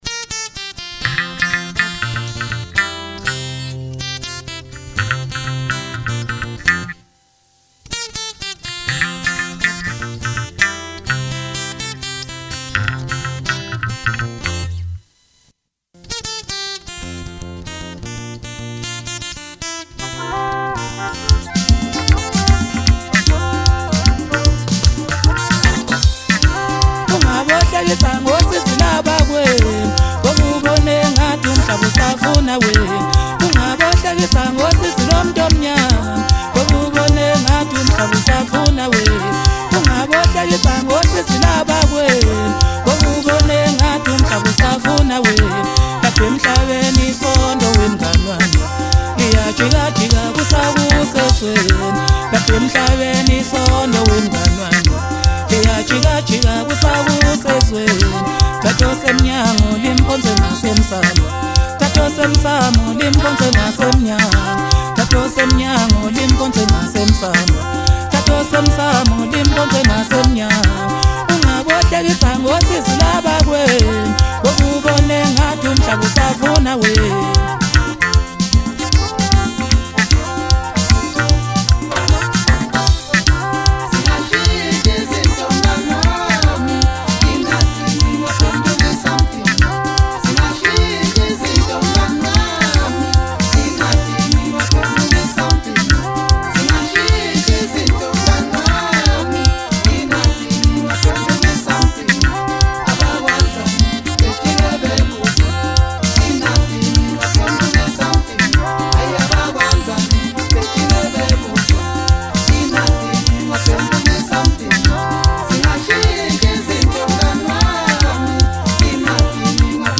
MASKANDI MUSIC
hit maskandi song